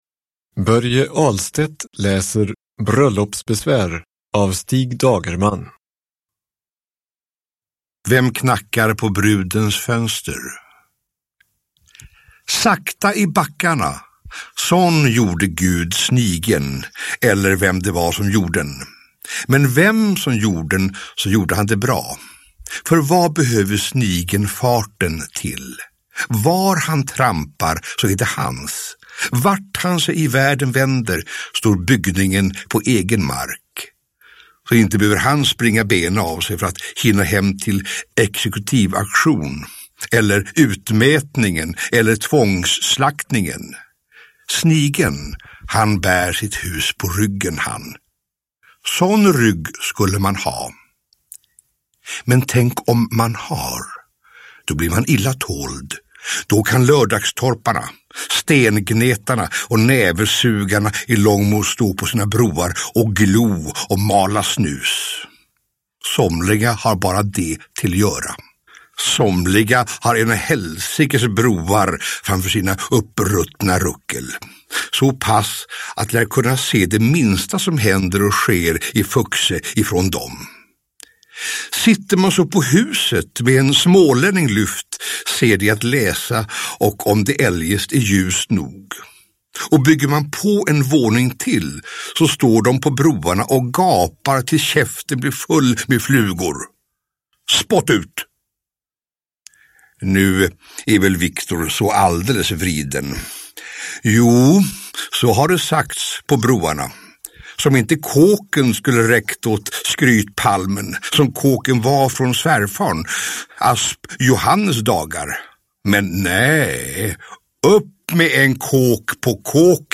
Uppläsare: Börje Ahlstedt